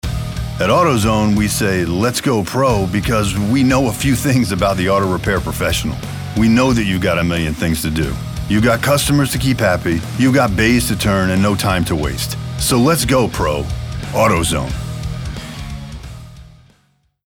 announcer, attitude, authoritative, Booming, bravado, cocky, confident, cool, Deep Voice, generation-x, gravelly, Gravitas, gritty, inspirational, Matter of Fact, middle-age, midlife, tough